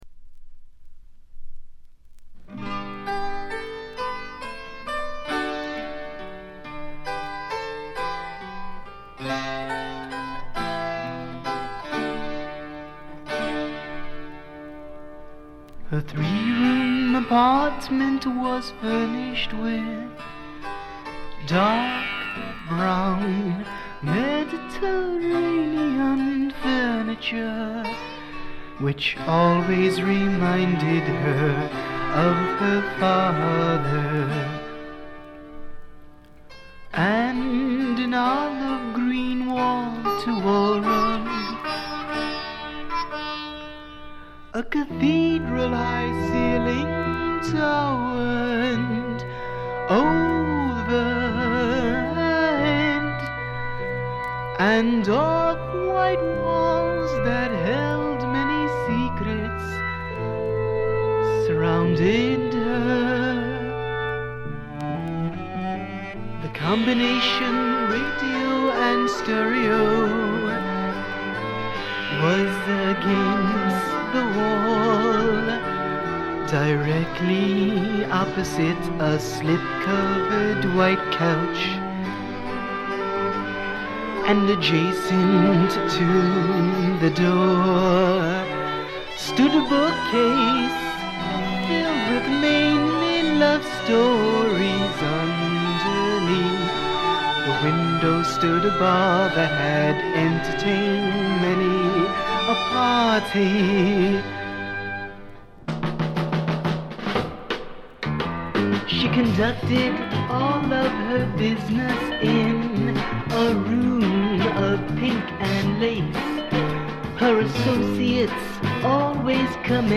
黒人臭さのまったくないヴォイスがまた素晴らしいです。
試聴曲は現品からの取り込み音源です。